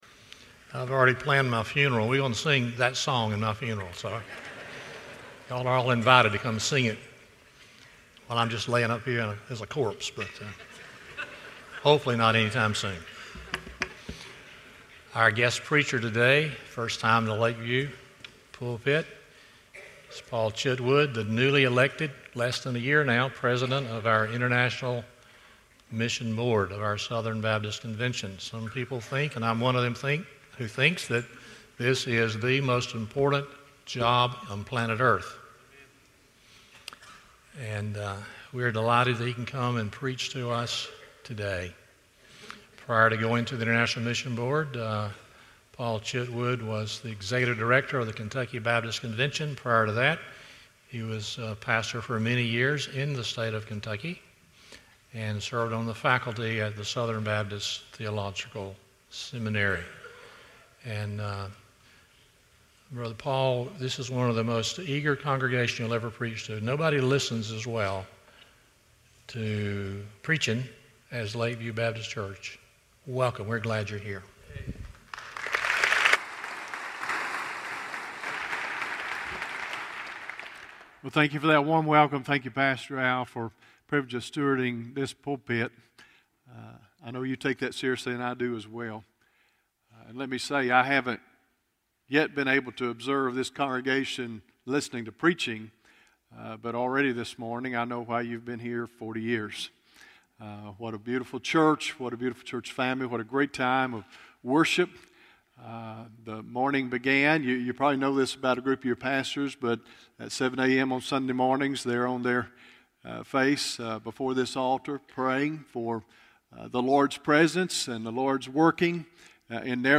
Revelation 7:9-10 Service Type: Sunday Morning 1.